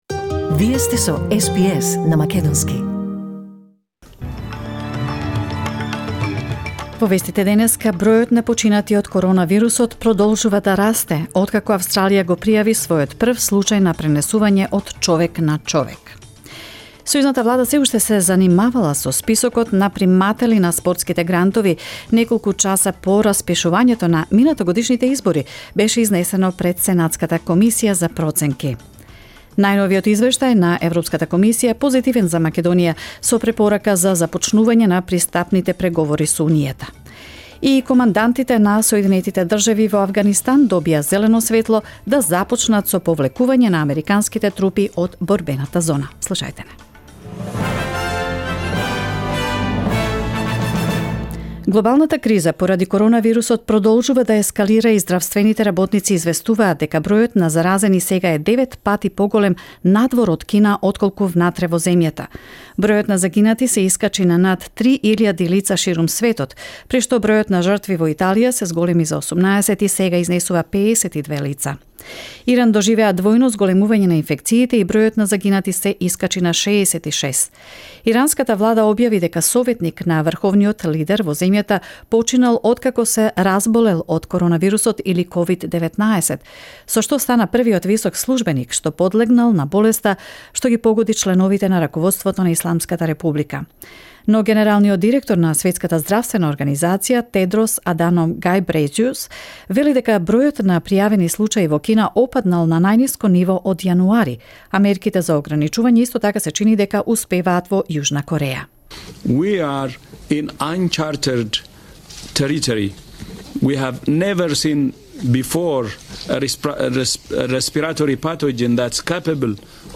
SBS News in Macedonian 3 March 2020